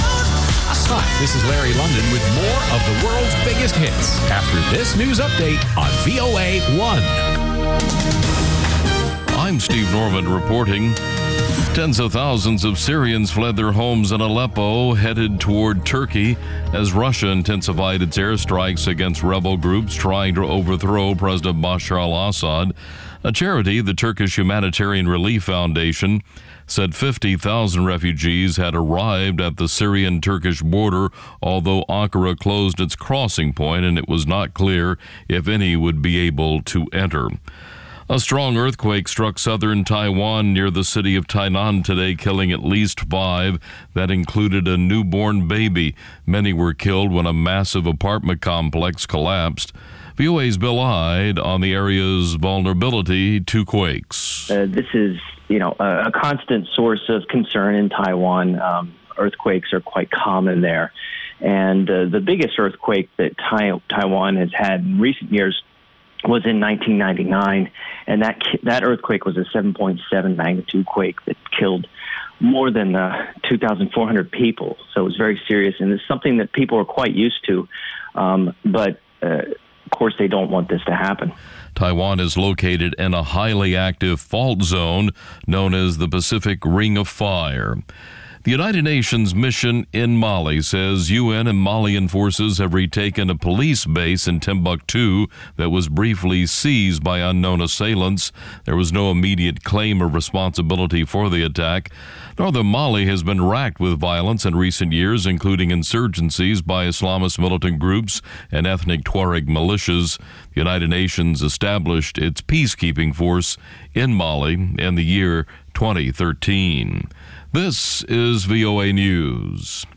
ყოველ შაბათს რადიო თავისუფლების პირდაპირ ეთერში შეგიძლიათ მოისმინოთ სპორტული გადაცემა „მარათონი“.